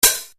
pootvoren· hajtku a vyrobφte jednoduch² ale pou╛ite╡n²